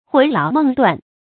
魂勞夢斷 注音： ㄏㄨㄣˊ ㄌㄠˊ ㄇㄥˋ ㄉㄨㄢˋ 讀音讀法： 意思解釋： 形容日夜思念，精神困乏。